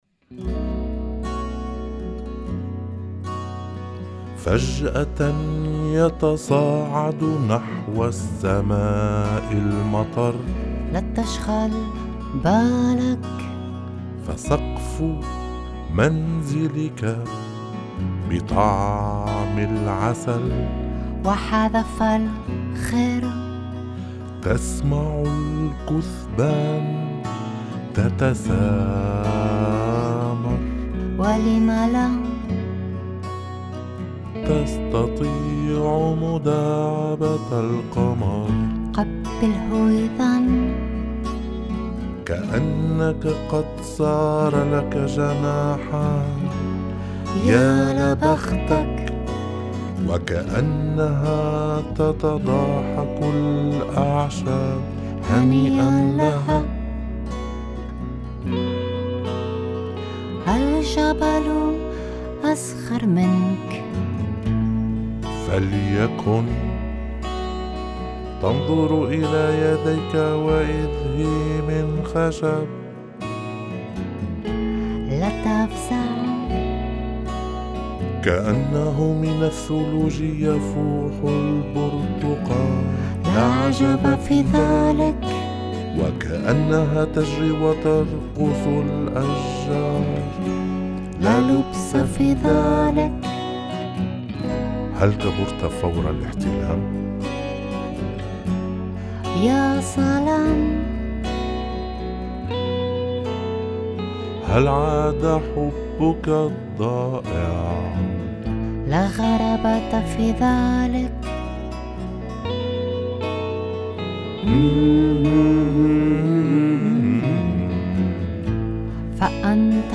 chanson en arabe